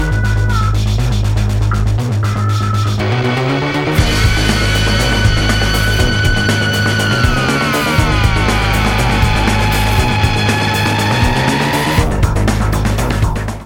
transposed, filtered, resonant, distorted, delay type effect
guitar
Sounds quite resonant.